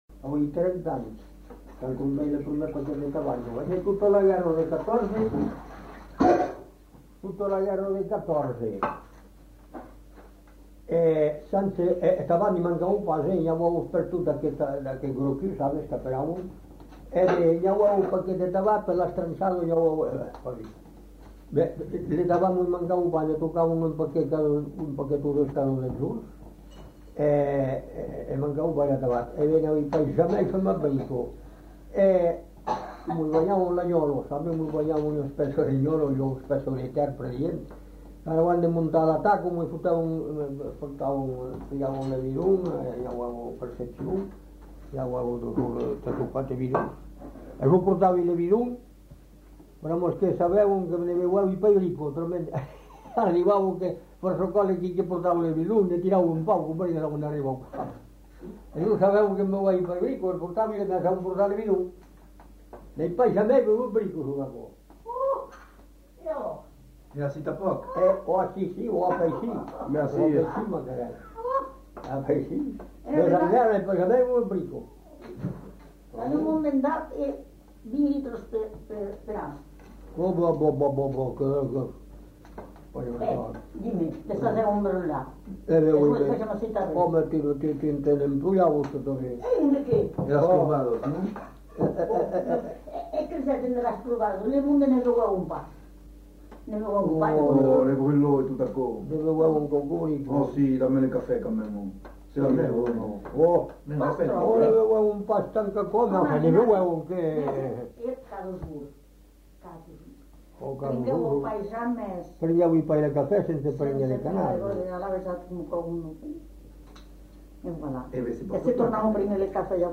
Lieu : Cazaux-Savès
Genre : témoignage thématique